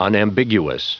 Prononciation du mot unambiguous en anglais (fichier audio)
Prononciation du mot : unambiguous